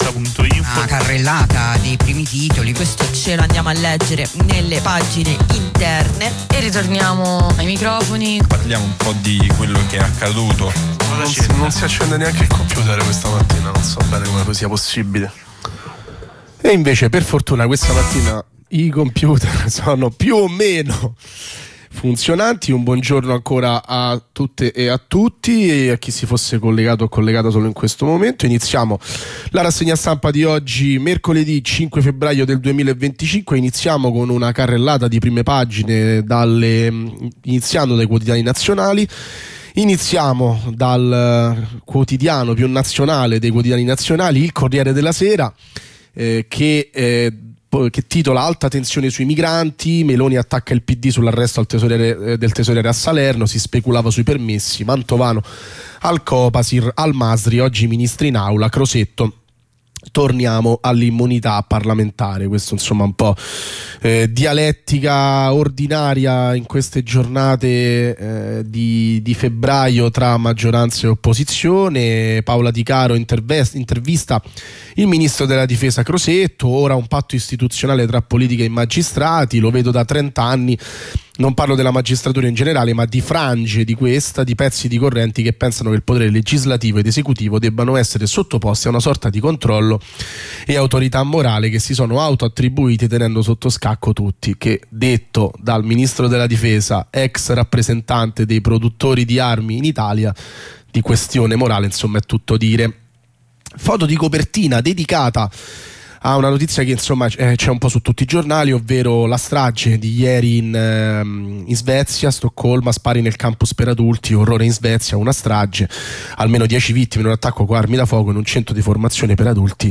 la rassegna stampa di mercoledì 5 febbraio 2025